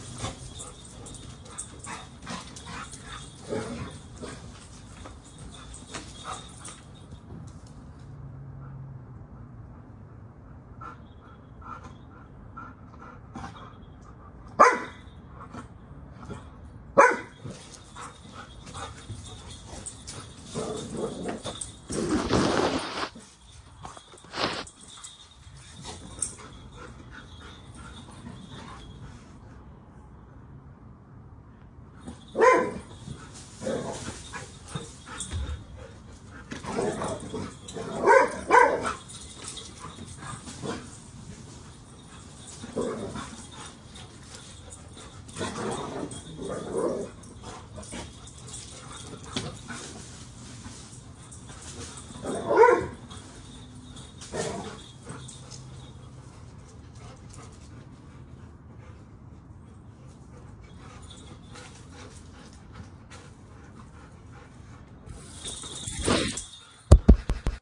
While baloo was on her fun walk Phoenix and Callisto decided to express their unbounded joy at being reunited once again, sharing it with the whole entire neighborhood!
I wonder which one was barking? it sounds like only one of them barked, but yes, there is deffinitly growly sounds too. they sound like they are having fun!